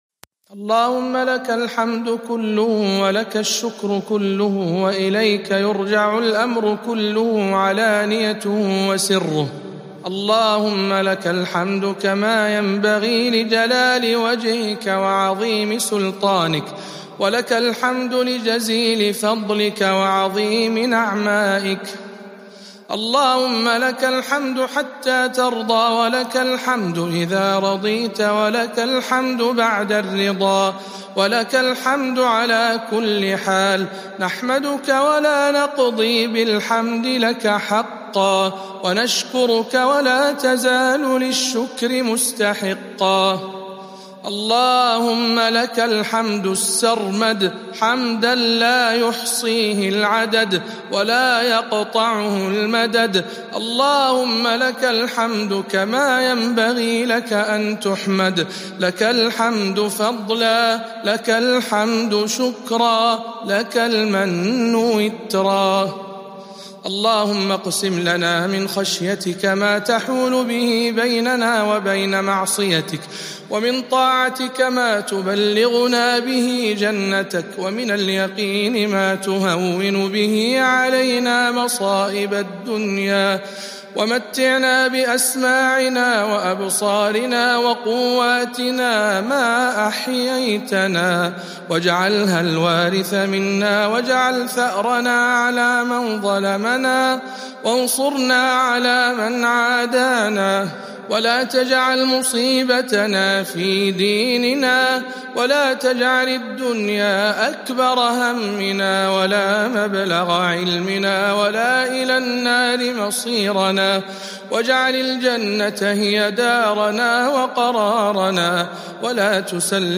أدعية القنوت (2) - رمضان 1442 هـ